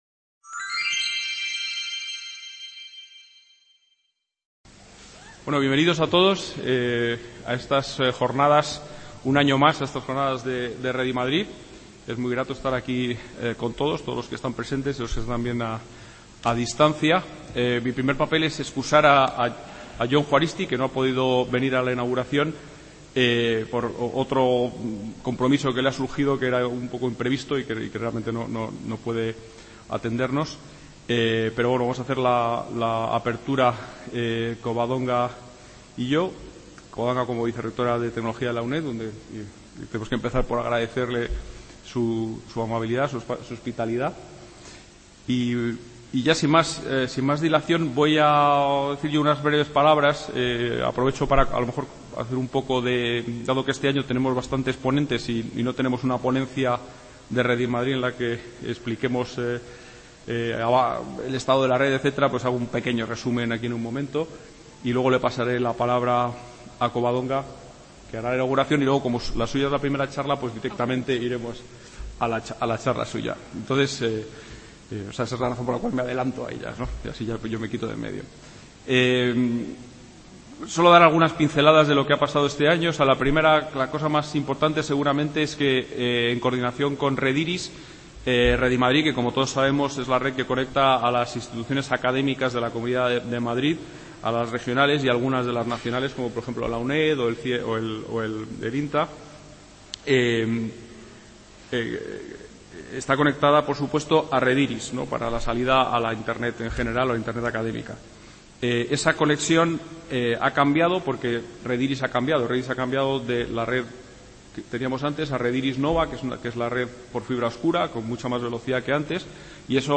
| Red: UNED | Centro: UNED | Asig: Reunion, debate, coloquio... | Tit: CONFERENCIAS | Autor:varios